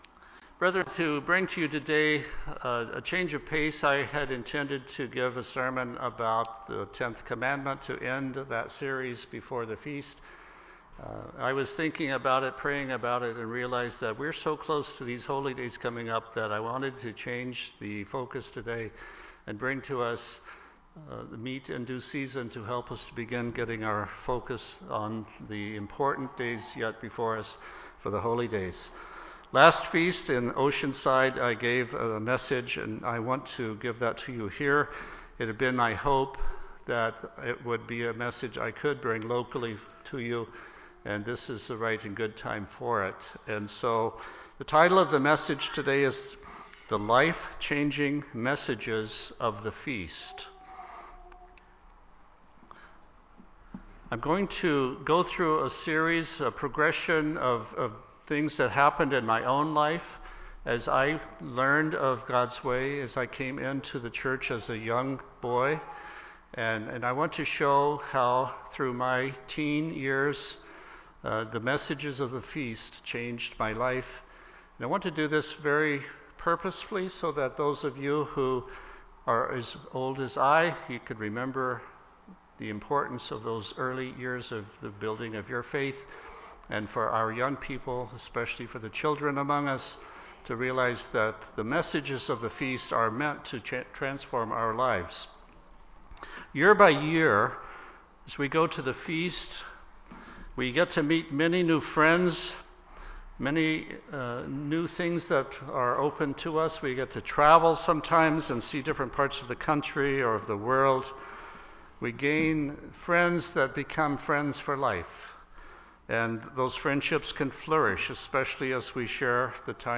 This sermon traces that process through the life and experiences of the speaker, encouraging each one to consider the mileposts along their journey, through the messages of the Feast.